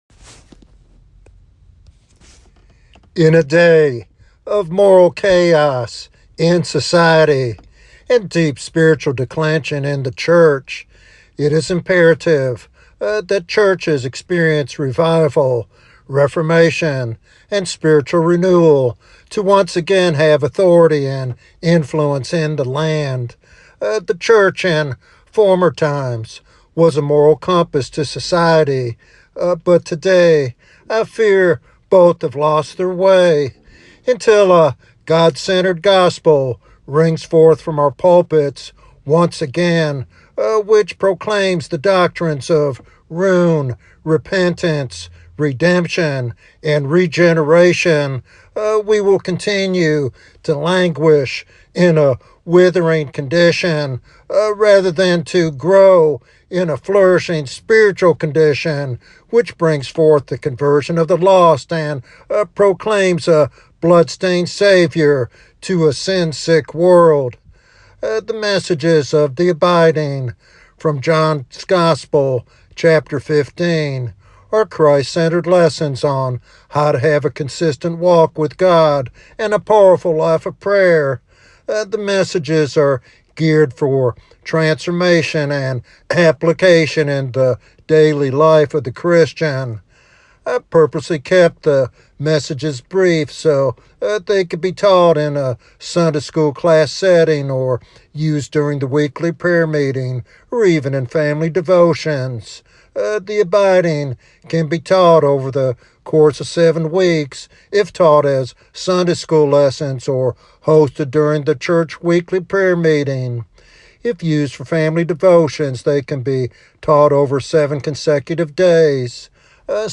This sermon equips believers and church leaders to foster revival, deepen their walk with God, and bear lasting fruit for their communities.